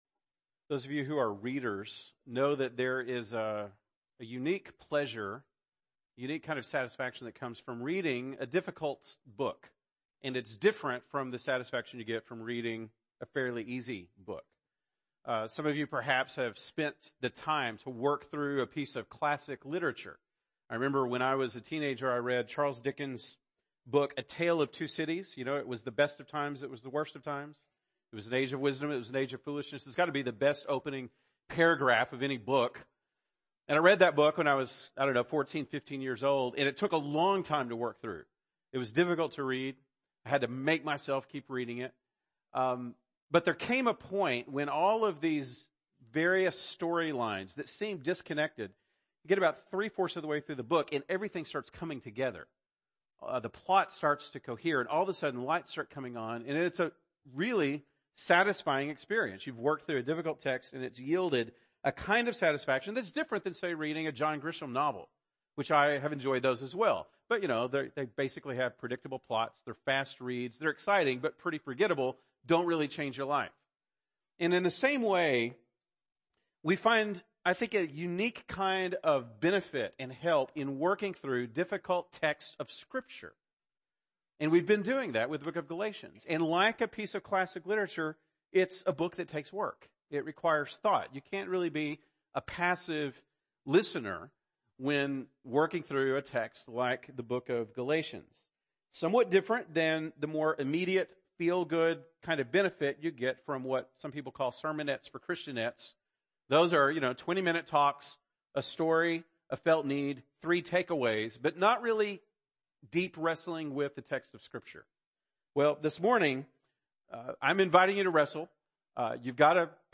November 12, 2017 (Sunday Morning)